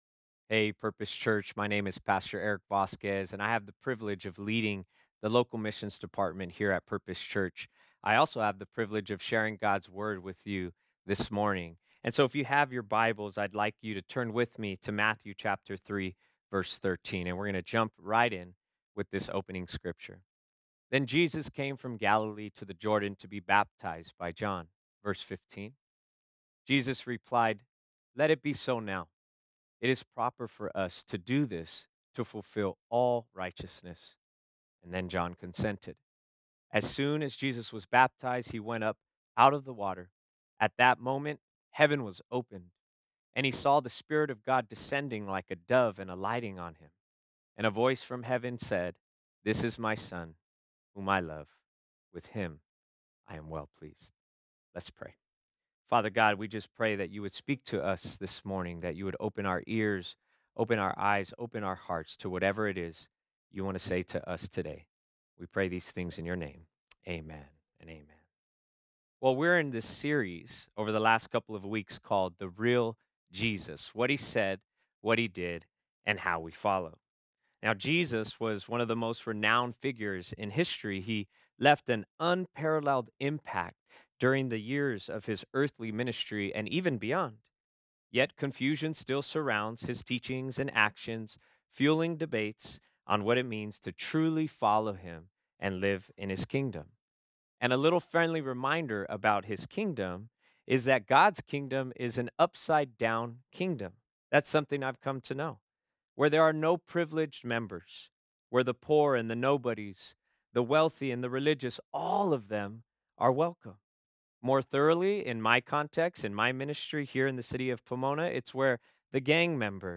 Traditional Worship (In-Person Service)